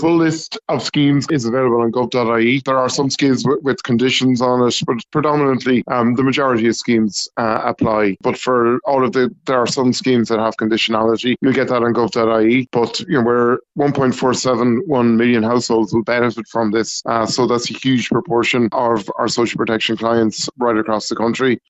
Minister for Social Protection, Dara Calleary says over a million households will benefit from the support………….